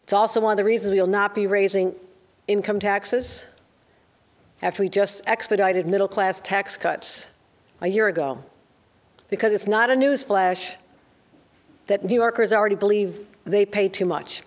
During her budget announcement, the governor told New Yorker’s that despite this spending and the possibility of heading into a recession, their income taxes won’t be going up.